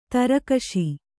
♪ tarakaṣi